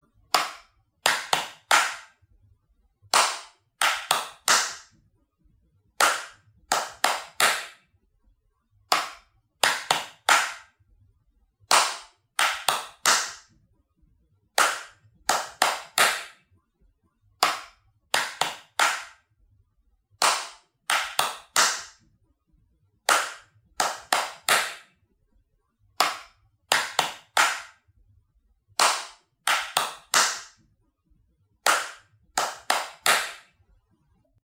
Tiếng Vỗ tay mầm non theo tiết tấu phối hợp
Tiếng Vỗ tay theo tiết tấu Chậm, Nhanh, Phối hợp Âm thanh Chào mừng Lên xe (cho Xe Khách)
Thể loại: Tiếng động
Description: Tiếng vỗ tay mầm non theo tiết tấu phối hợp, nhịp điệu hài hòa, rộn ràng và vui tươi, thường dùng trong các bài hát như “Cả tuần đều ngoan”. Hiệu ứng âm thanh này tạo không khí sinh động, giúp trẻ cảm nhận nhịp vỗ 123 mở tay, vỗ 123 mở tay... vỗ đều, nhịp nhàng. Âm thanh vỗ tay đồng bộ, rõ ràng, hỗ trợ giáo viên giảng dạy, minh họa bài hát, luyện khả năng phối hợp vận động và nhịp điệu cho trẻ, phù hợp sử dụng trong dựng video, âm nhạc, hoạt động lớp học mầm non.
tieng-vo-tay-mam-non-theo-tiet-tau-phoi-hop-www_tiengdong_com.mp3